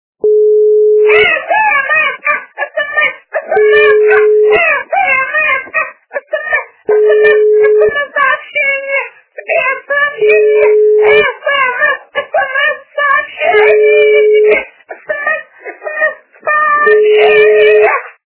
Мальчик с сорванным голосом - S-M-S-ка! (длинная версия) Звук Звуки Хлопчик із зірваним голосом - S-M-S-ка! (довга версія)
» Звуки » звуки для СМС » Мальчик с сорванным голосом - S-M-S-ка! (длинная версия)